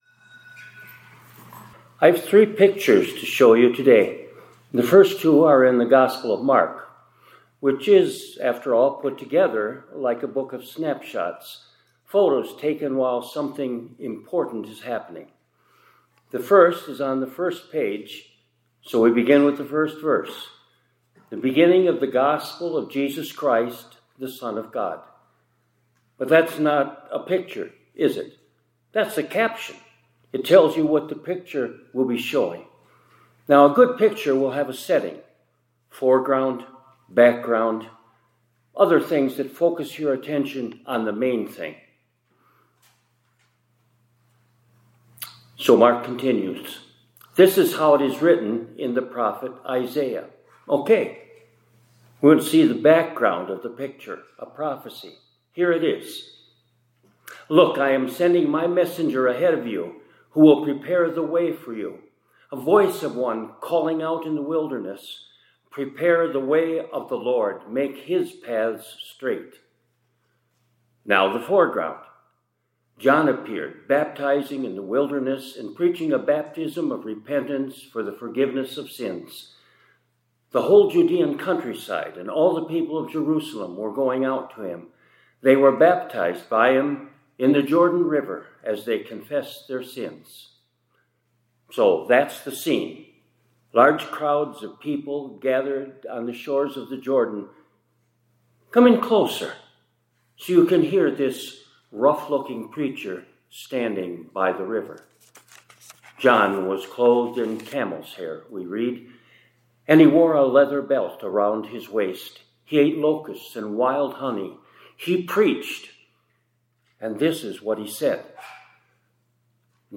2026-01-22 ILC Chapel — Snapshots of the Savior